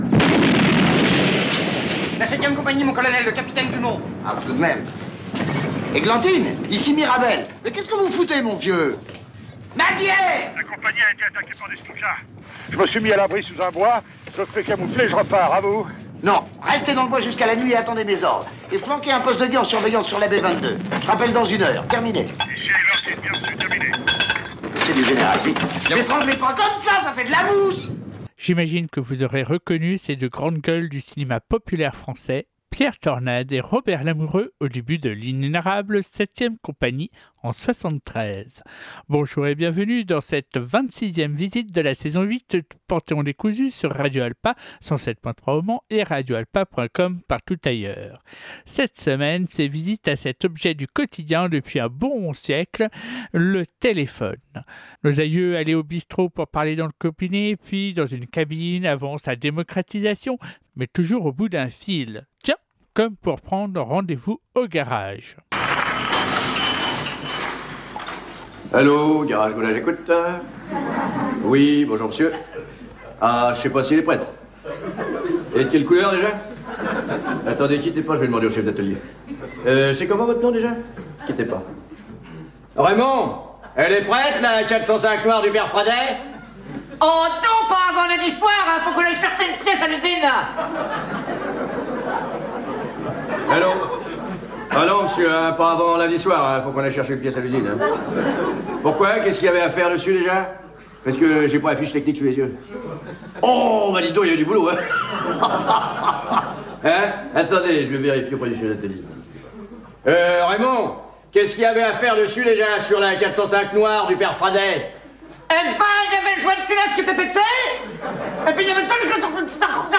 Des sons cultes, du hip-hop, pas mal d’humour, du gros métal, des surprises, y compris en chansons et un reportage de la fin du XXe siècle dans le futur de nos quotidiens !